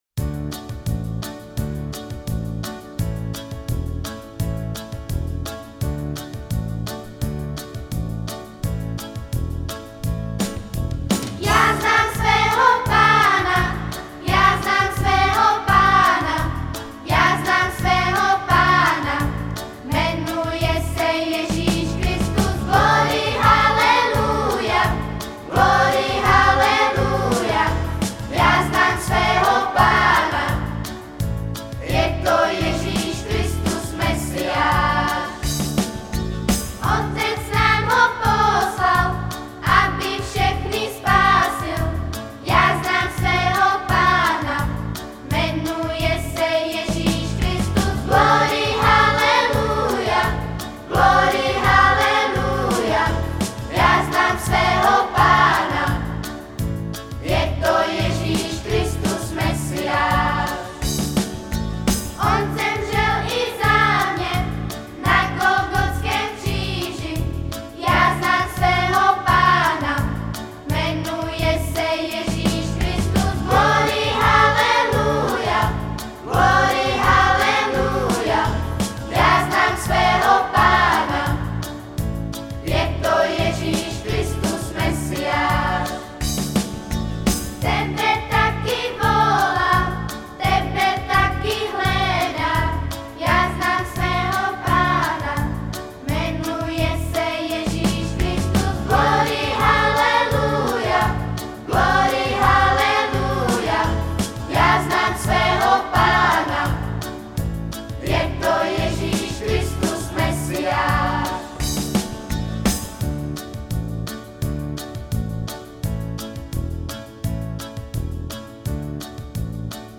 Křesťanské písně
Písničky pro děti